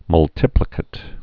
(mŭl-tĭplĭ-kĭt)